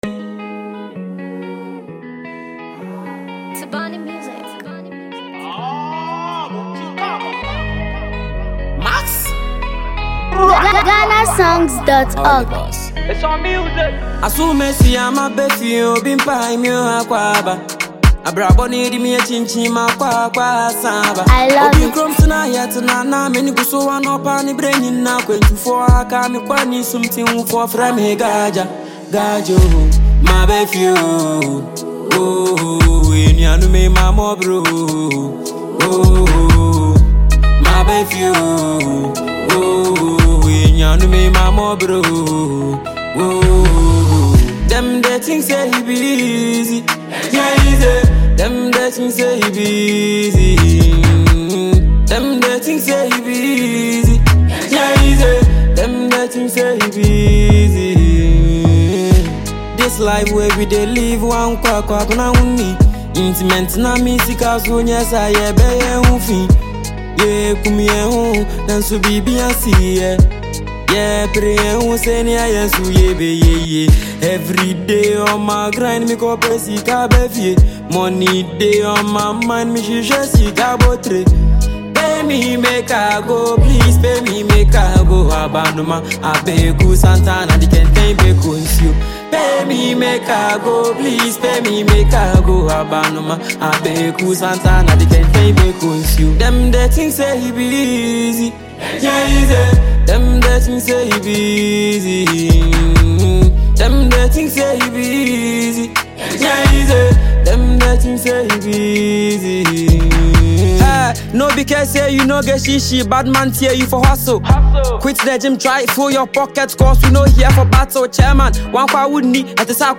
With a confident delivery and unapologetic lyrics
The production blends hard beats with a gritty street vibe